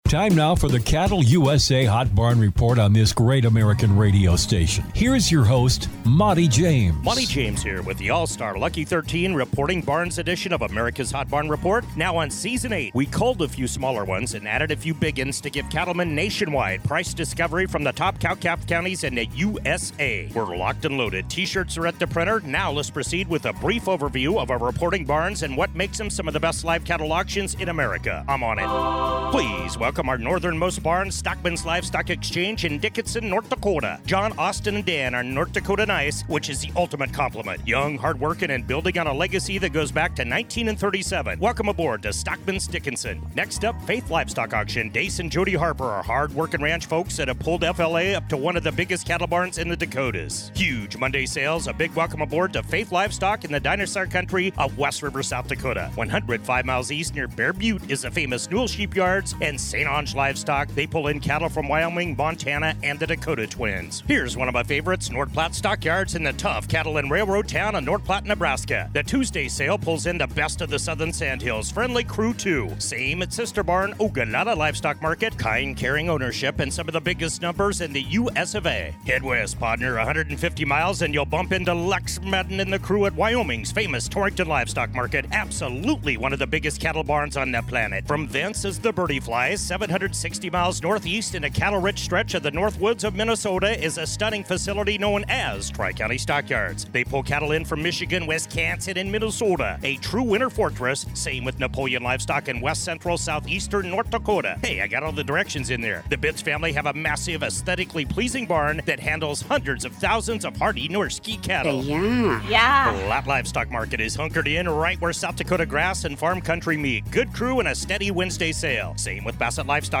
The Hot Barn Report features interviews with industry leaders, market analysts, producers and ranchers and features True Price Discovery from salebarns in Great Northern Beef Belt and across the nation.